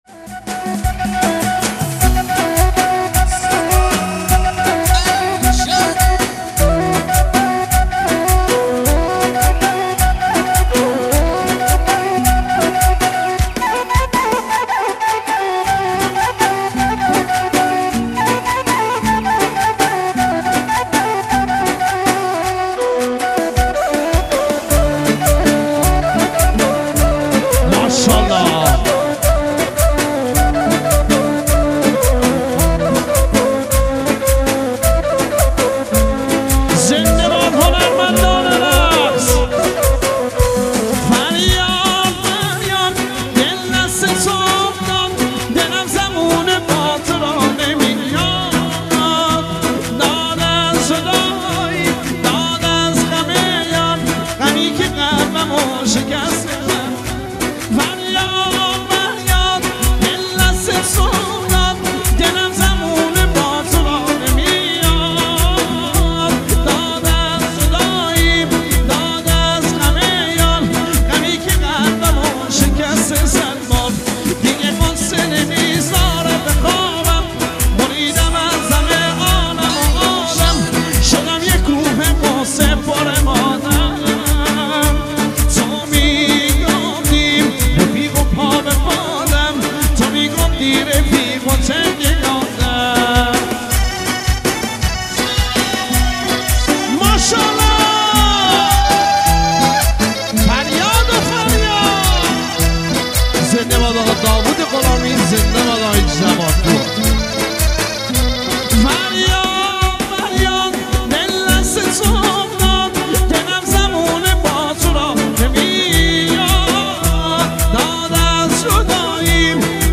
آهنگ کرماتجی